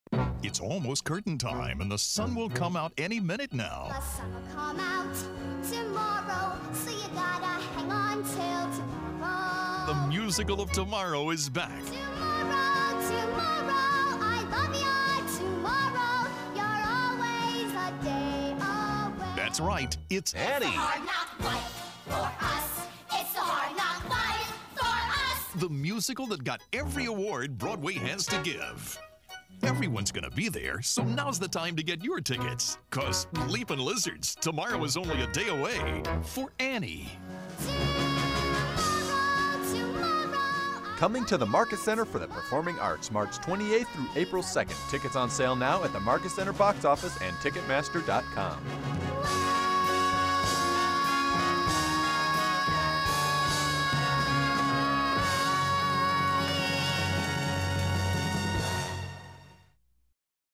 Annie Radio Commercial